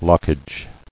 [lock·age]